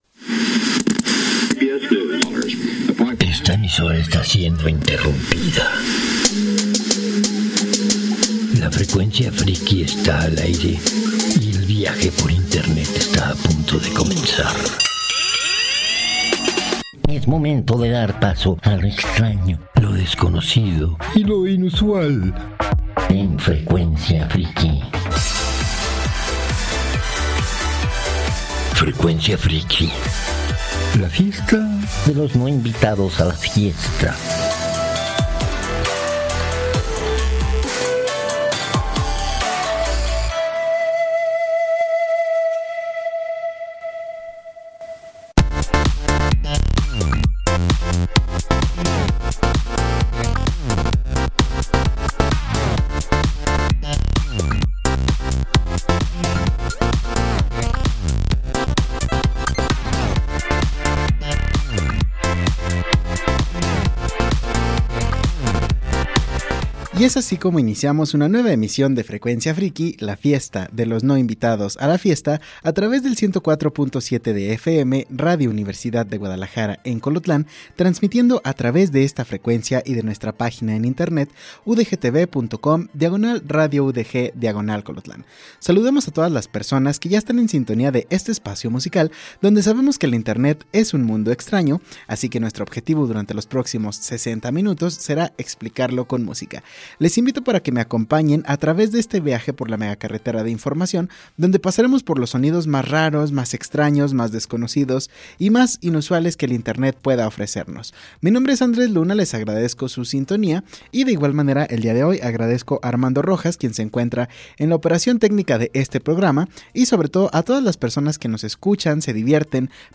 es un lugar donde nos encanta la música del mundo (pero sobretodo de China, Corea del Sur y Japón); es un programa para lo extraño, lo desconocido y lo inusual.